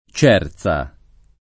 [ ©$ r Z a ]